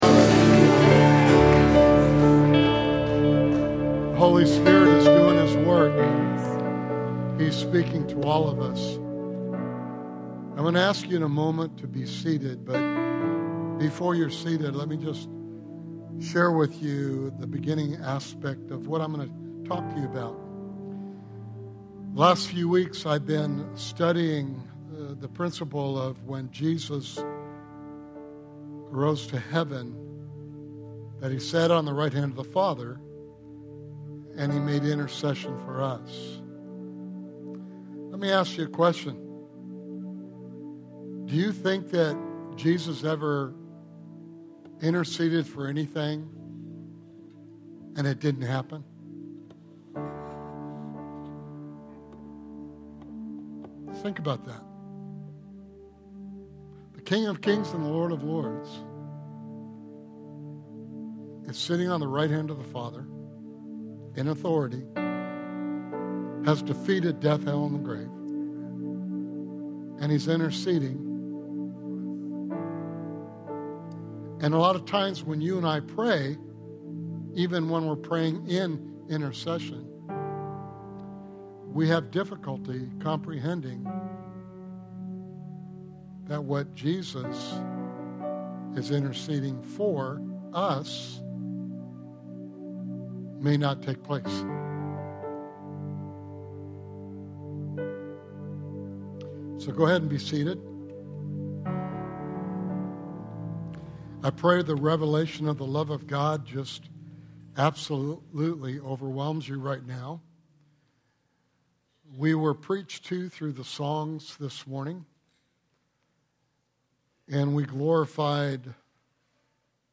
Sermon Series: The Parable of the Lost and Found